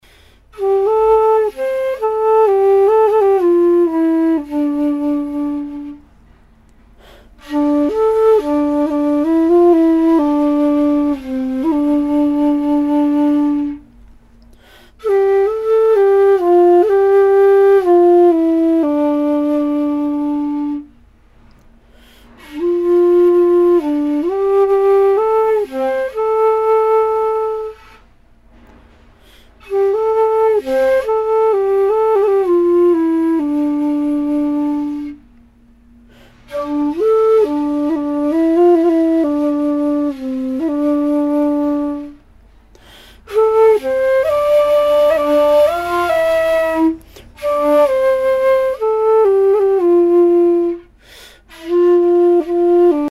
干声试听